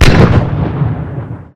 plane_preexp2.ogg